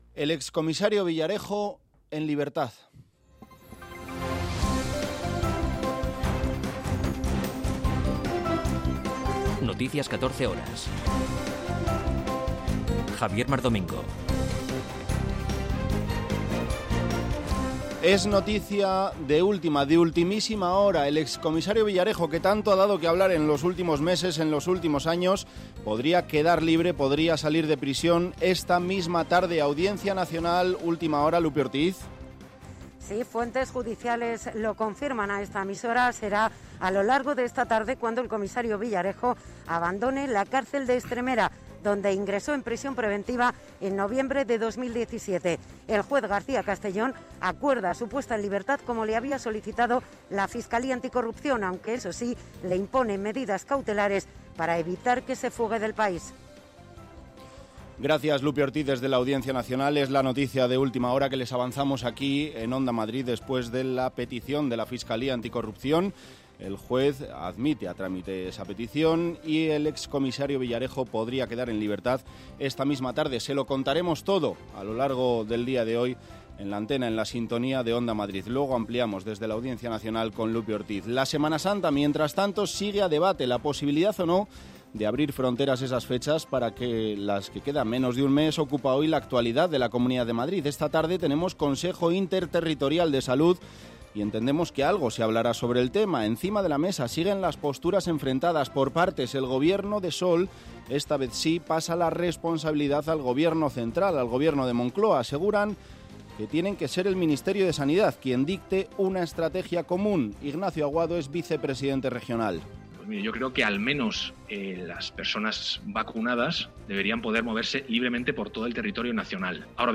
Noticias 14 horas 03.03.2021